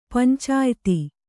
♪ pancāyti